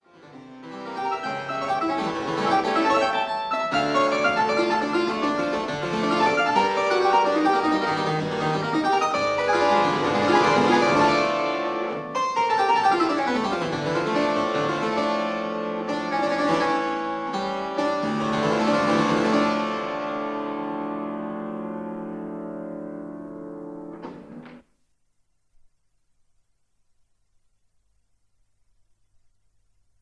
a two manual harpsichord